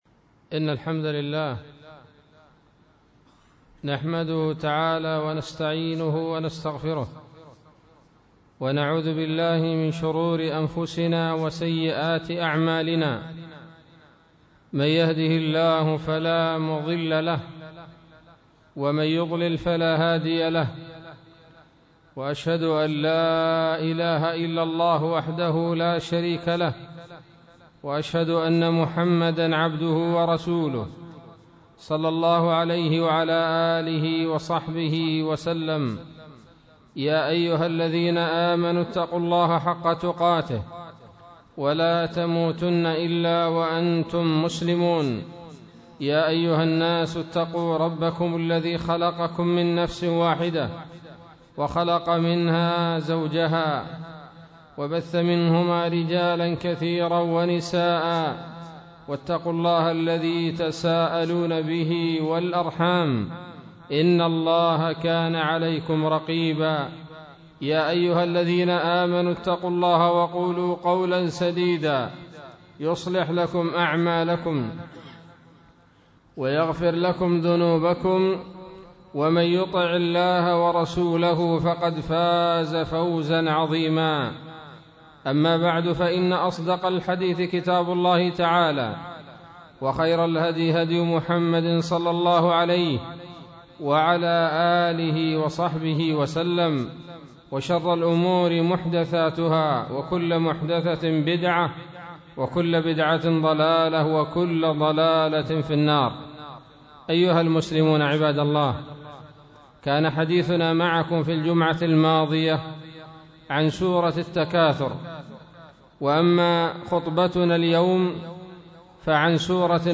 خطبة بعنوان : ((تفسير سورة العصر)) 28 ربيع الأول 1437 هـ